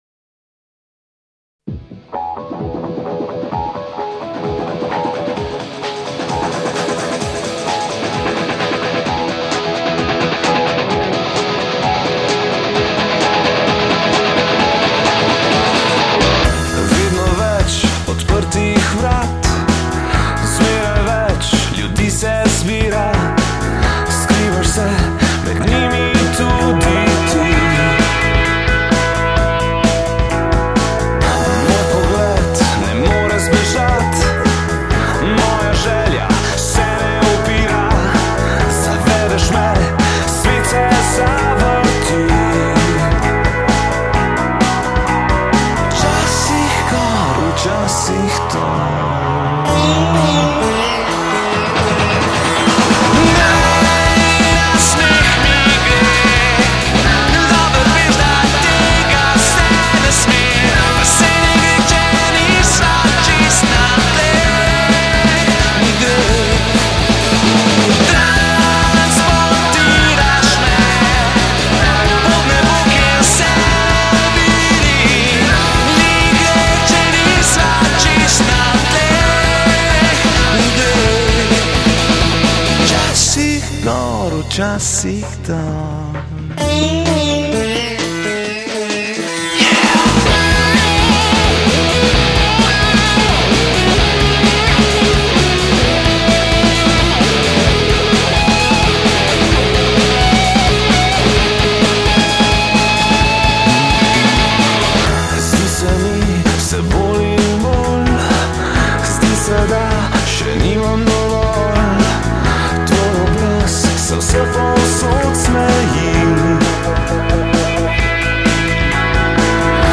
vokal
bobni
kitara, klaviature
pop-rock skupina